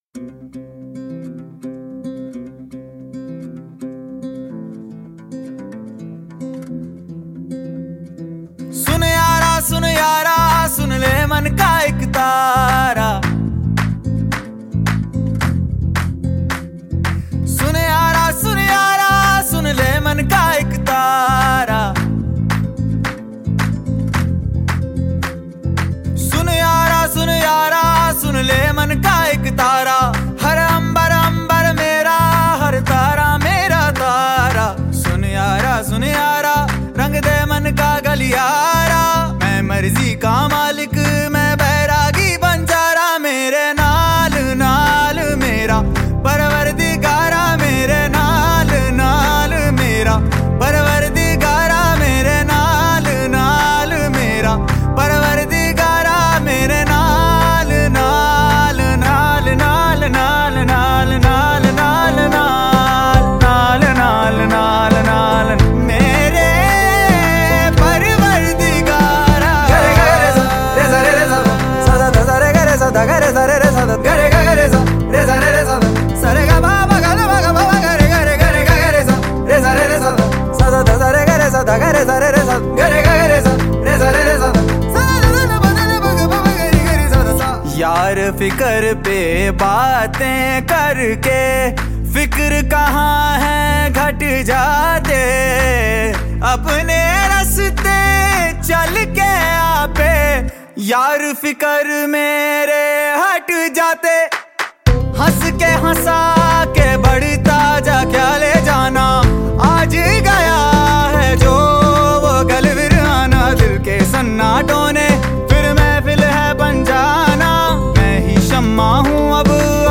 Hindi